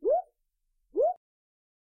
دانلود آهنگ قورباغه در مرداب از افکت صوتی انسان و موجودات زنده
دانلود صدای قورباغه در مرداب از ساعد نیوز با لینک مستقیم و کیفیت بالا
جلوه های صوتی